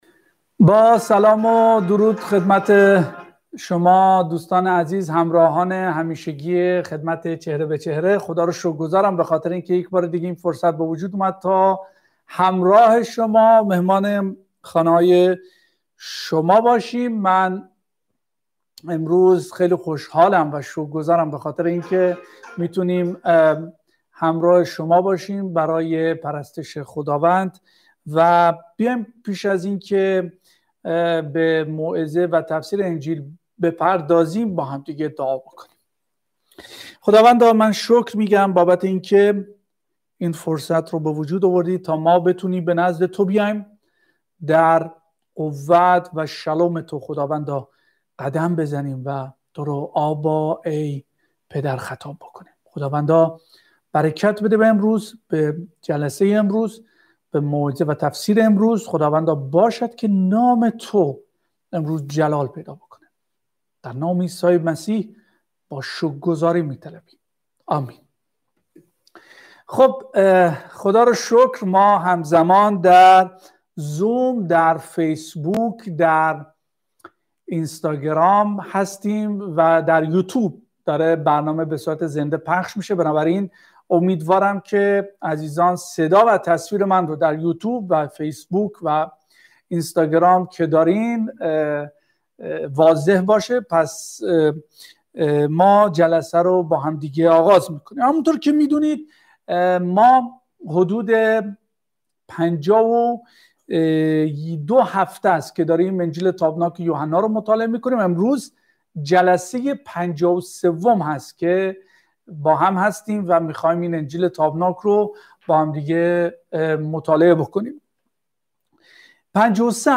۴۱-۱:۹ تفسیر و موعظه تشریحی انجیل یوحنا ۵۳ | قسمت دوم